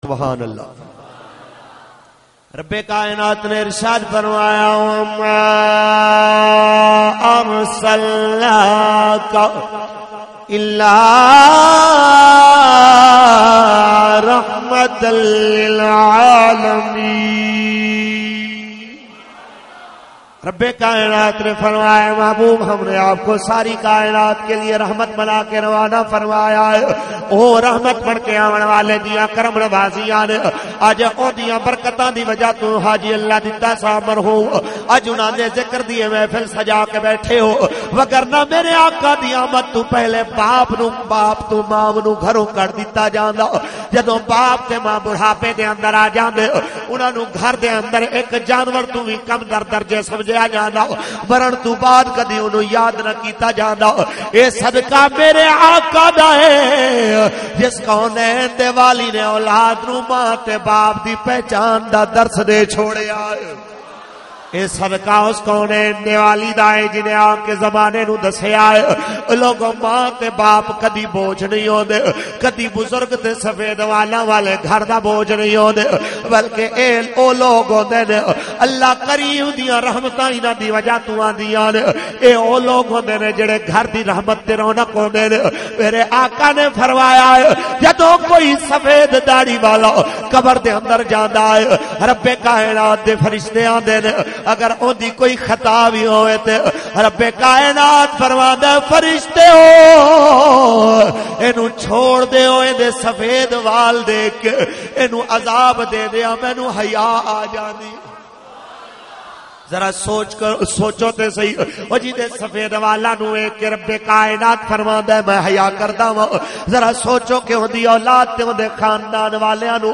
Serat e Muhammad SAW bayan mp3